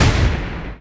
1 channel
EXPL_SML.mp3